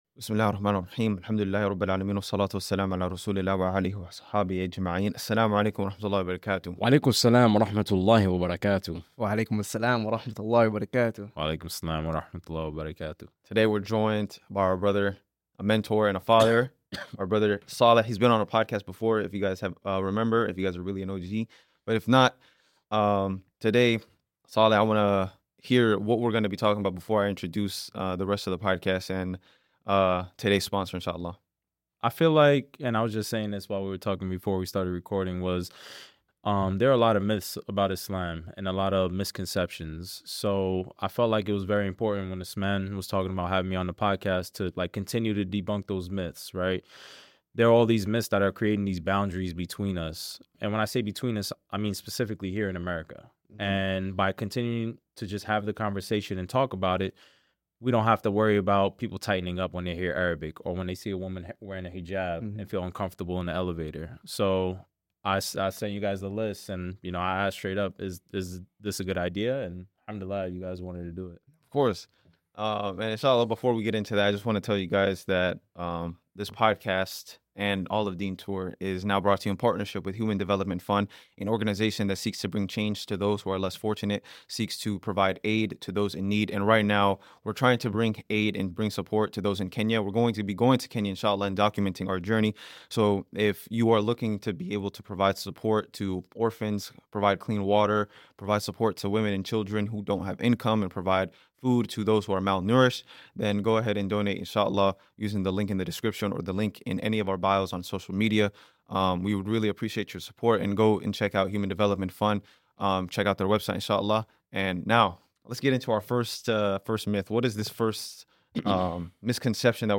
In this podcast we bring on a special guest and address some very common misconceptions about Islam.